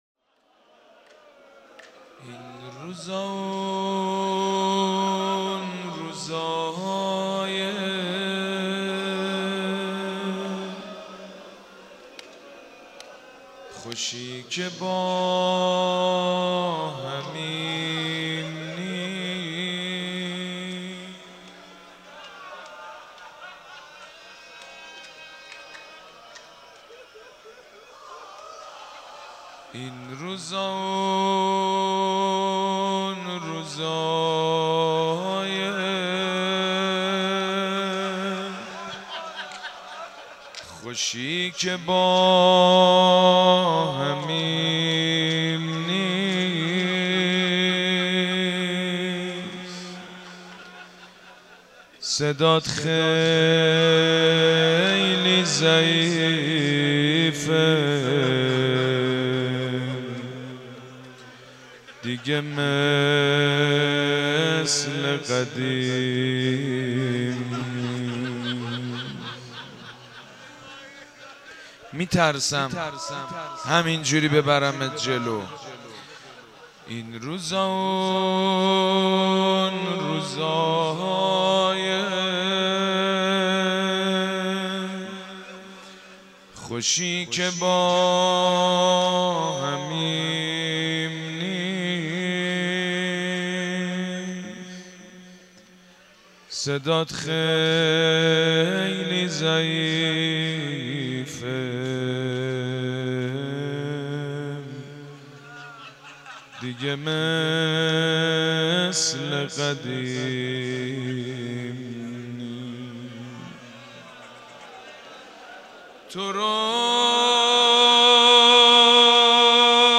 روضه
روضه.mp3